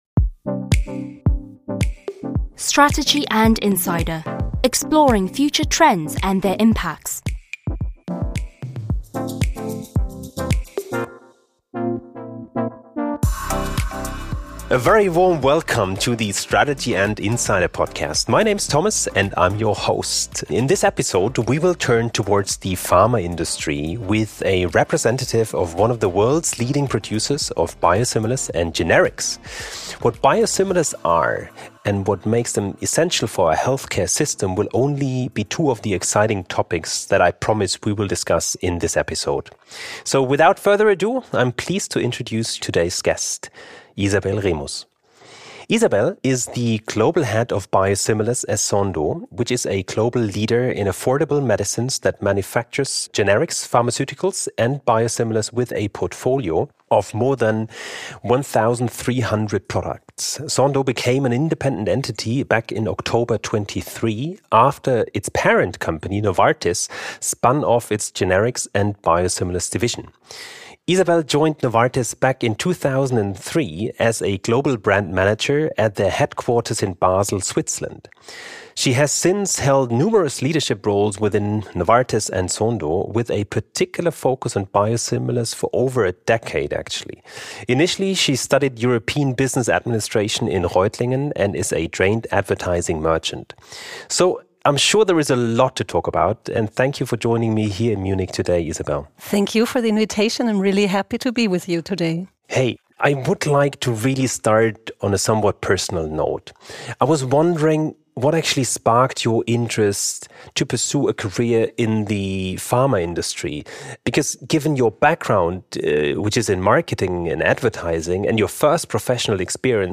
Strategy& talks to industry experts and leading practitioners from different sectors.